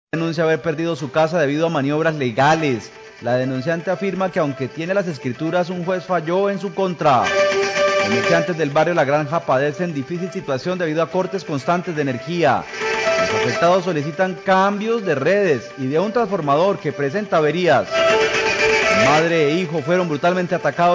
Radio
No hubo desarrollo de la nota durante la emisión del informativo.